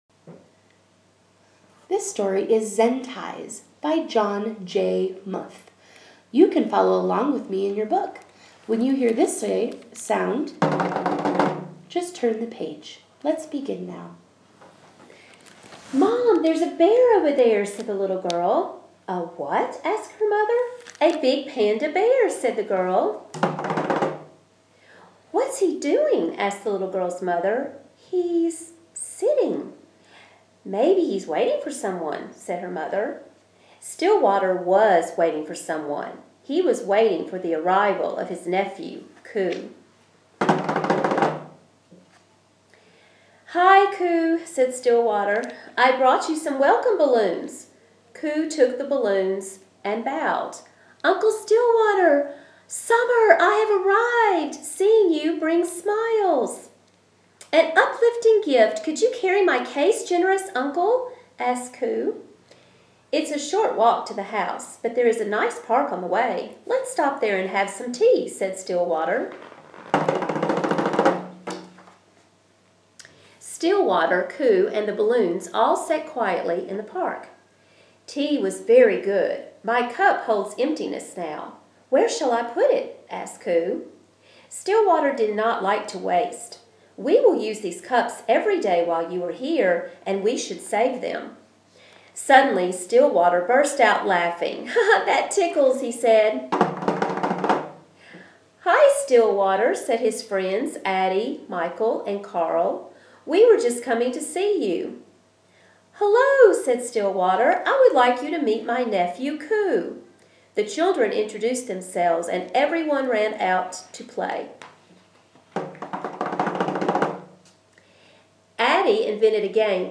Children’s Book Narration/Zen Ties